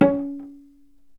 vc_pz-C#4-ff.AIF